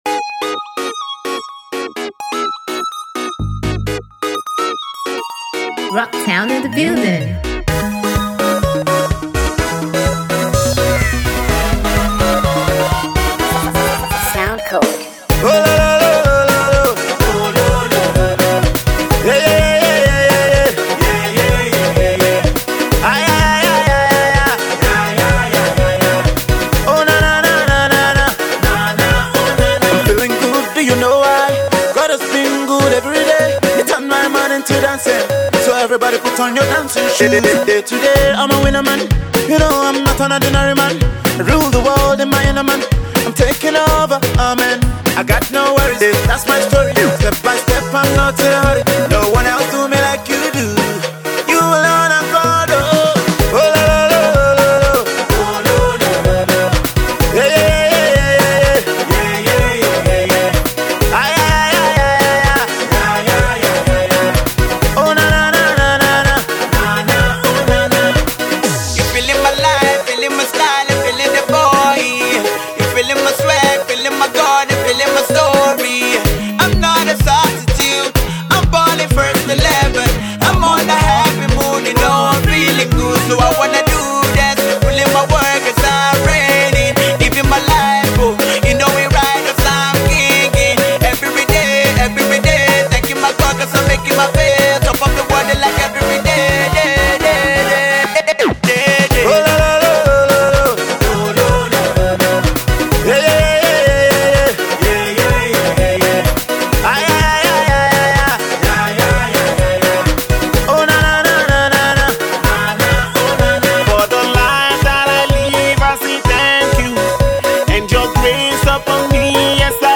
Straight off the studios
dance jam
This is one of those ‘feel good’ songs.